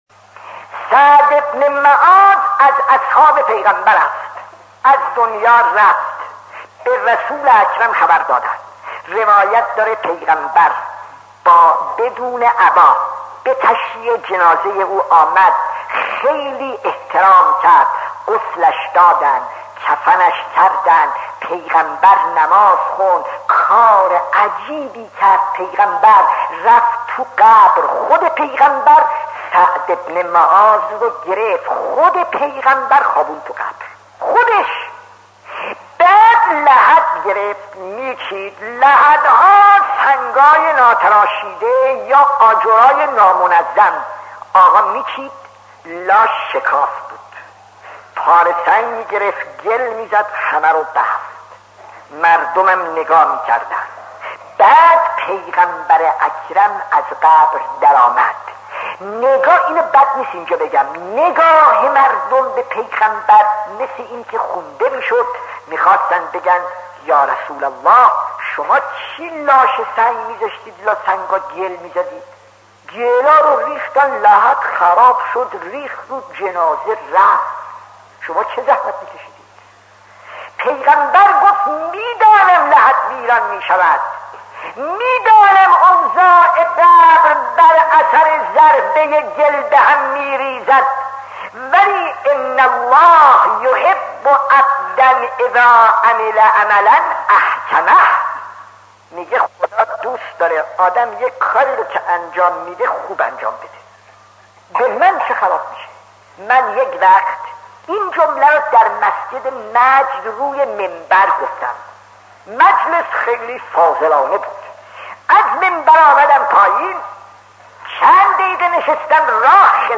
داستان 37 : سعد ابن معاذ از اصحاب پیامبر خطیب: استاد فلسفی مدت زمان: 00:07:38